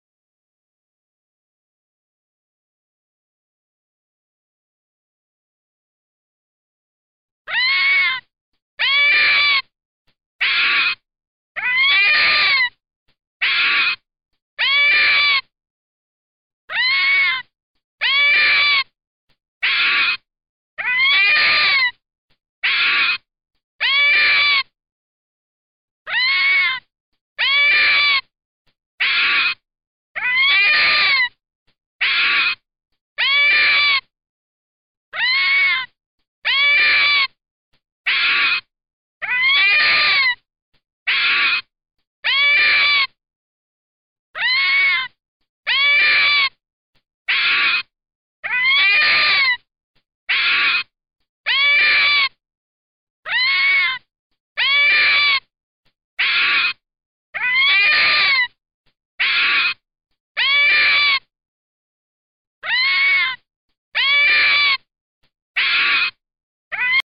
На этой странице собраны разнообразные звуки лисы: от реалистичных рычаний и тявканий до весёлых детских песенок.
Звуки фенека лисы с большими ушами